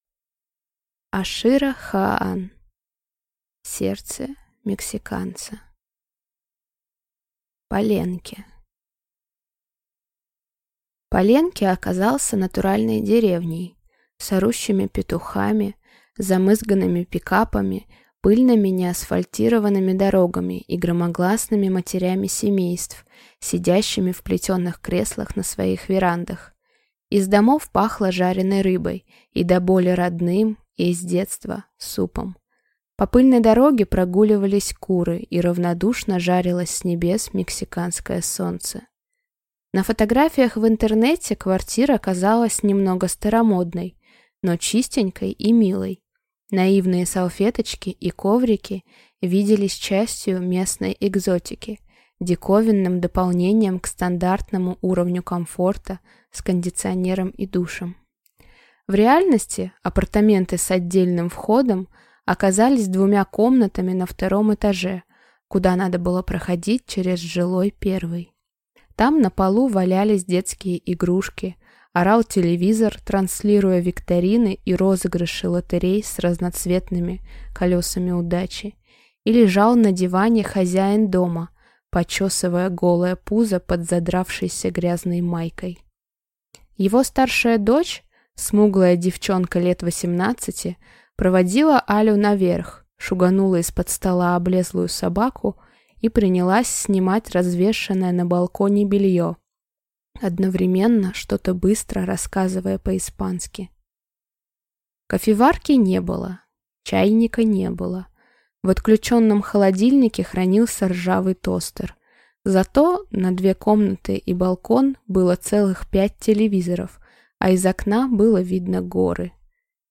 Аудиокнига Сердце мексиканца | Библиотека аудиокниг